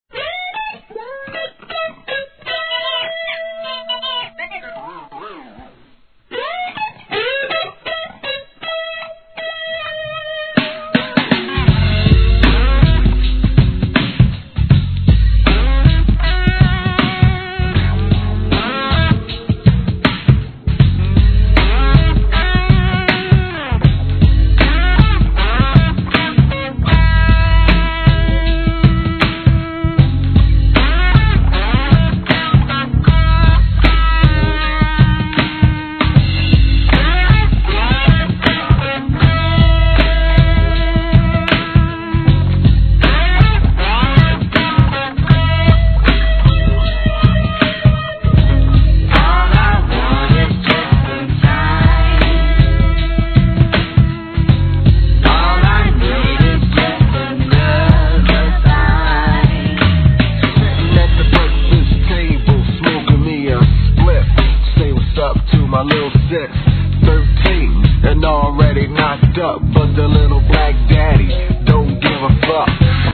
HIP HOP/R&B
1993年、ゆったりと刻むBEATにギターが絡みつく男の哀愁ナンバー!!